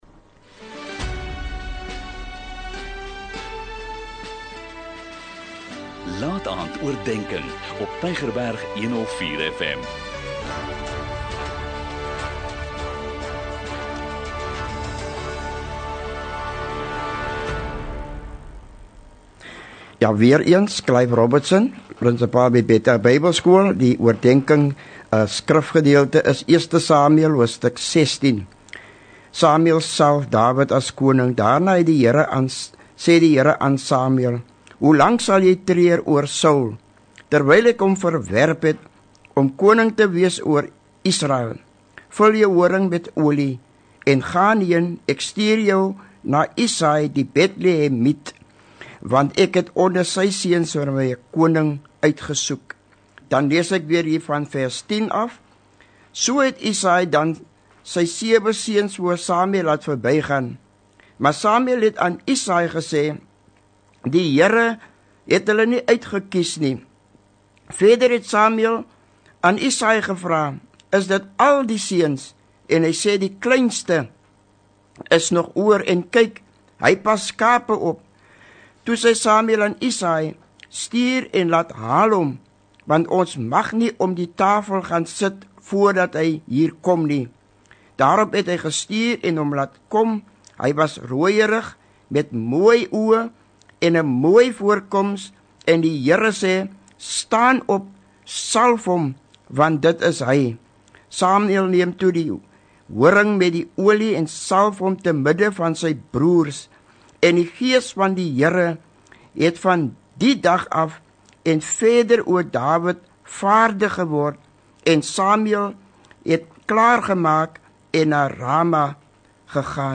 n Kort bemoedigende boodskap, elke Sondagaand om 20:45, aangebied deur verskeie predikers.